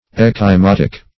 Ecchymotic \Ec`chy*mot"ic\, a. Pertaining to ecchymosis.
ecchymotic.mp3